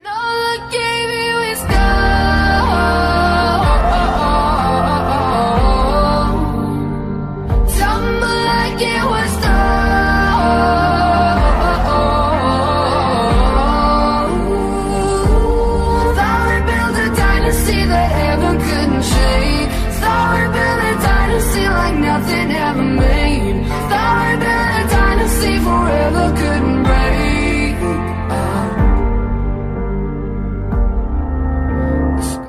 • Качество: 192, Stereo
красивые
женский вокал
грустные
спокойные